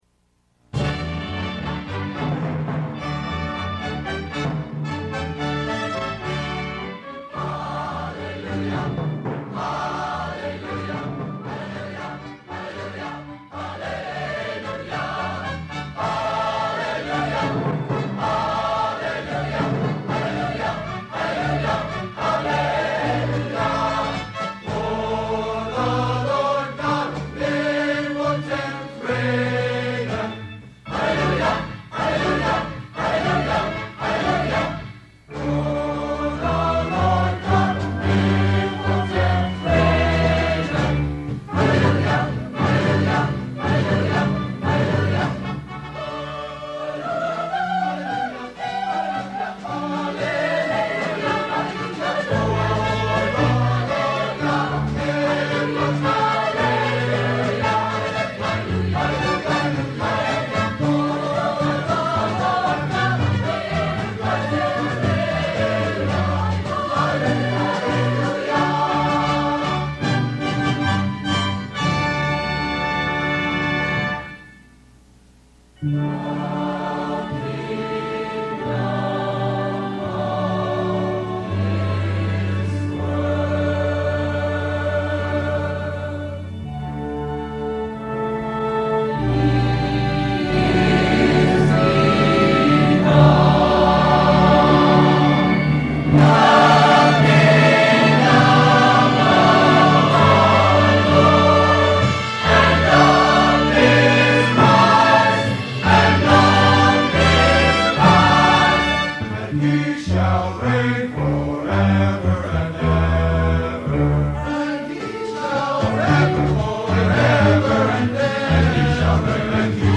Show Headline Tony Alamo Show Sub Headline Tony And Susan The Stamps Quartet Tony Alamo with Tony Alamo World Wide Ministries Tony & Susan Alamo with The Stamps Quartet Tony & Susan Alamo with one of the many appearances from The Stamps Quartet at one of the church services in Dyer, Arkansas 8/12/79. J.D. Sumner & The Stamps are well known for being Elvis Presley's backup singers.